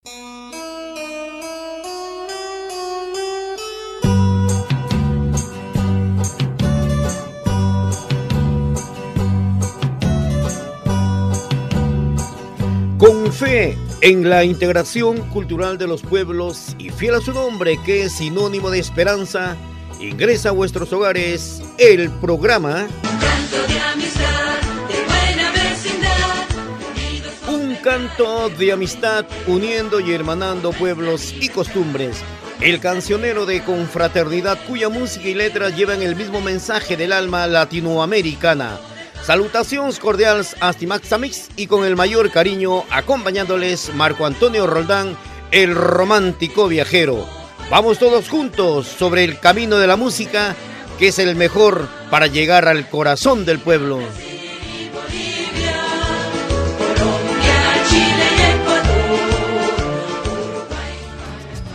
Careta i inici del programa
Musical